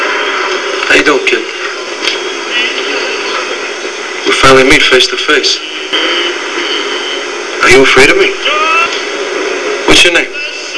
Name - (Chazz from Bronx Tale, Whats your name? speech) 232KB